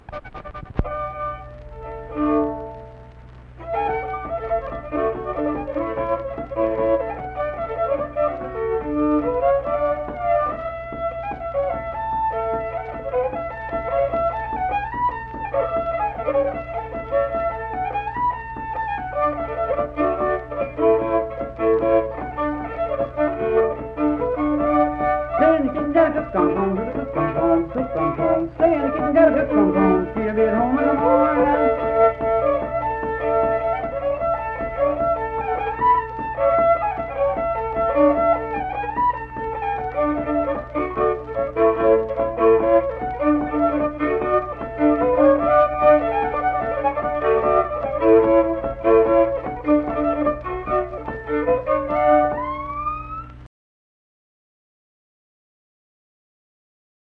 Instrumental Music
Fiddle, Voice (sung)
Kirk (W. Va.), Mingo County (W. Va.)